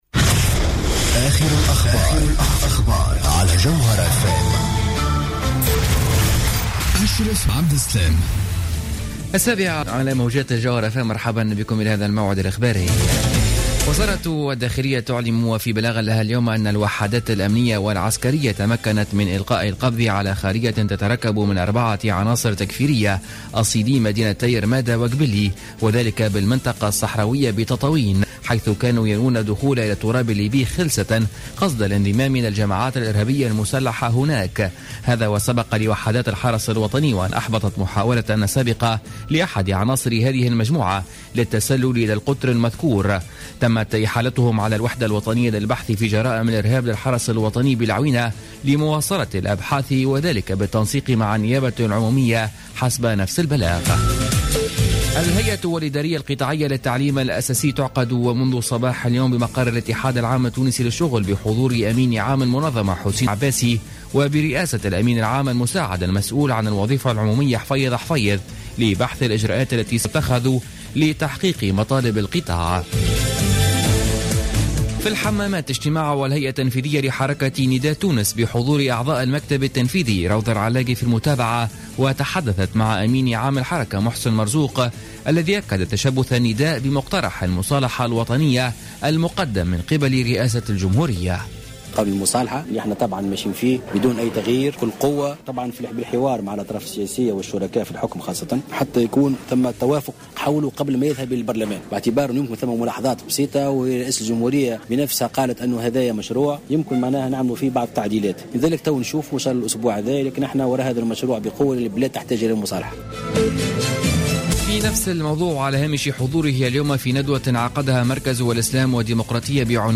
نشرة أخبار السابعة مساء ليوم السبت 22 أوت 2015